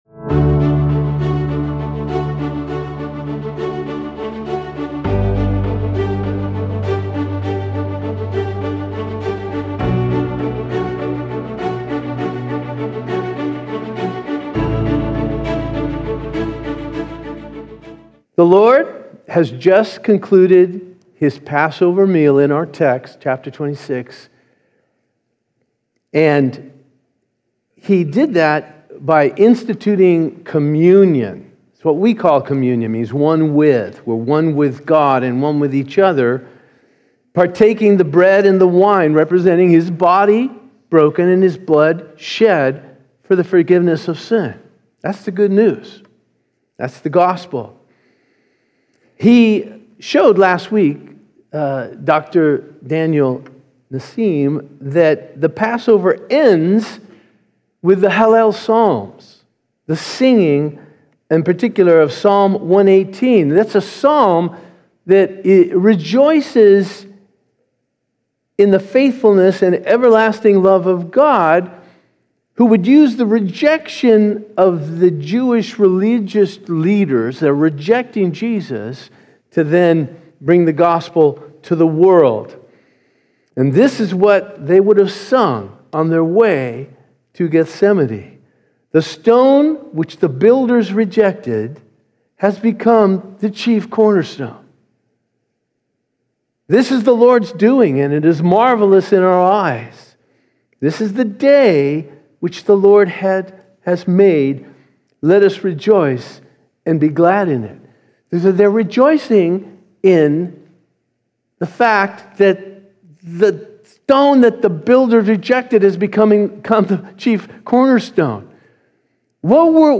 This is an audio podcast produced by Calvary Chapel Eastside in Bellevue, WA, featuring live recordings of weekly worship services.